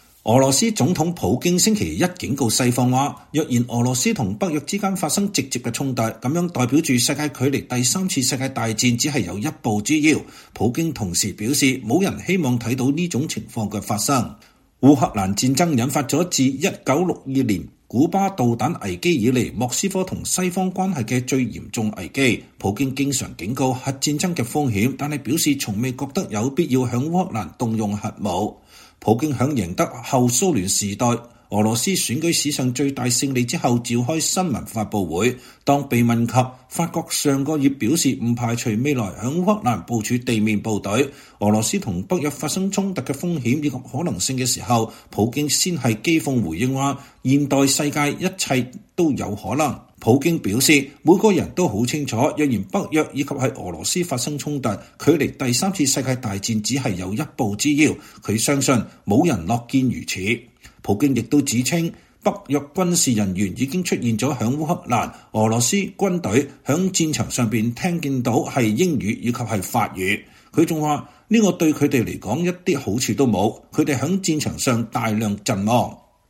2024年3月18日，普京在俄羅斯總統大選後，在莫斯科的一個競選總部發表演說。